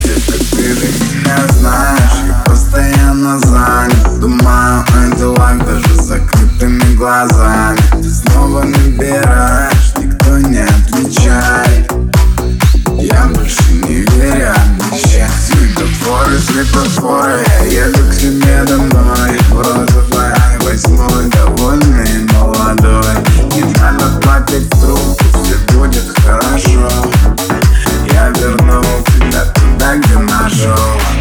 громкие
remix
Club House